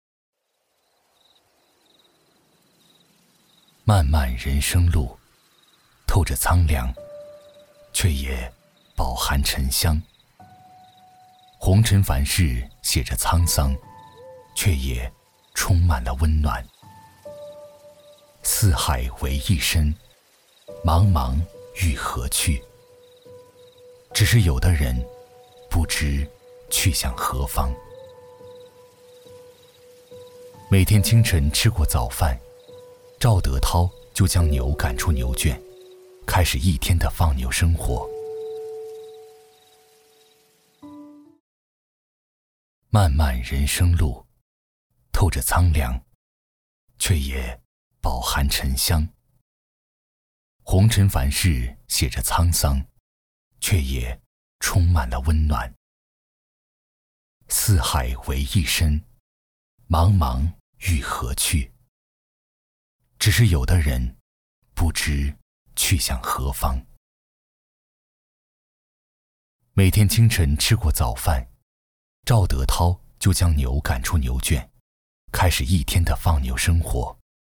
226男-年轻自然 走心
特点：年轻自然 走心旁白 GM动画
风格:亲切配音